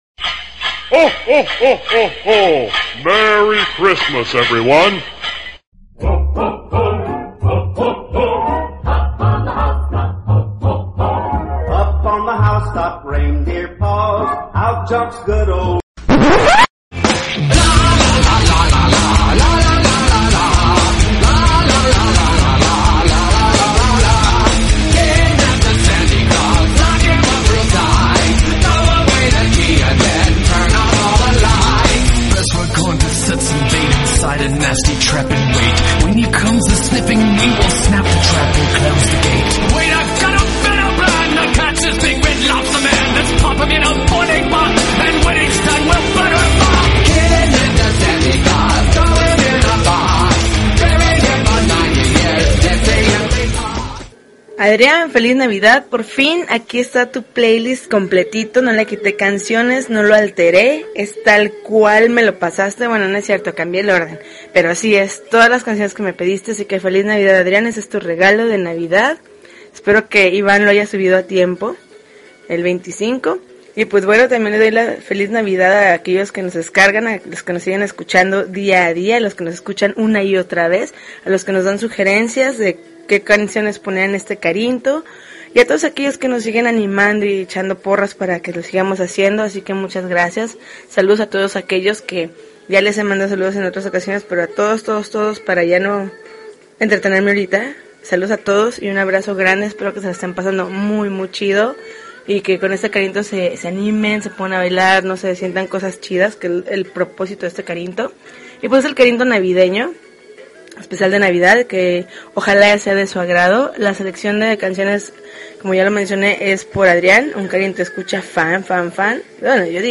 December 24, 2012Podcast, Punk Rock Alternativo